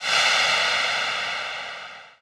bulkhead sfx
air_hiss.wav